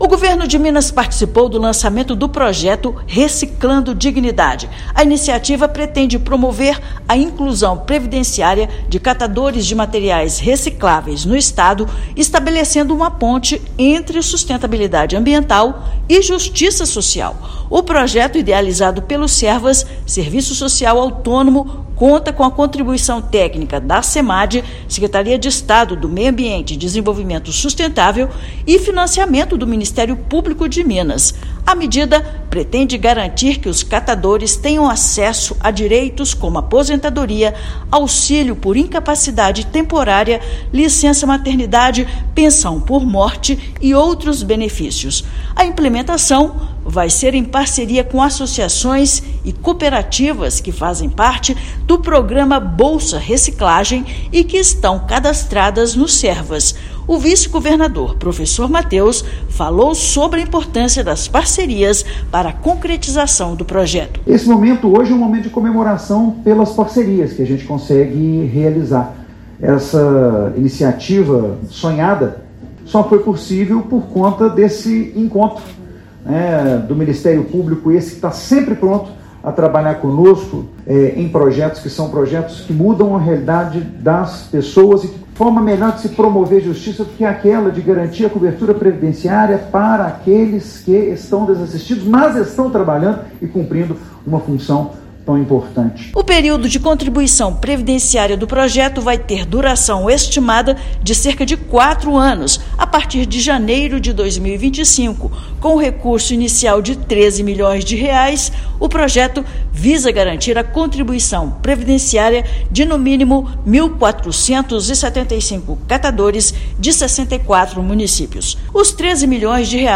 “Reciclando Dignidade” é fruto de parceria com Servas e Ministério Público de Minas Gerais. Ouça matéria de rádio.